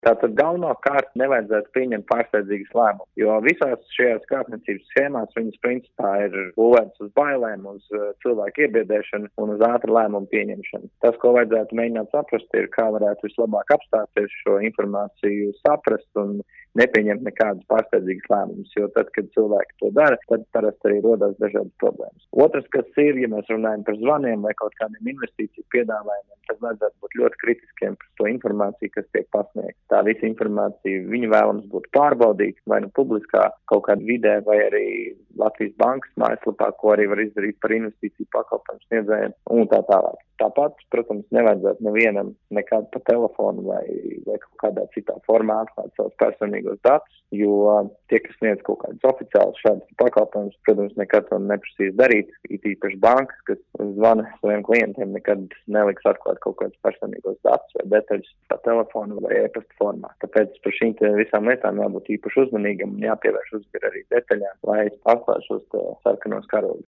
RADIO SKONTO Ziņās par to, ko darīt ja saņemat aizdomīgu, iespējams krāpniecisku zvanu